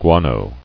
[gua·no]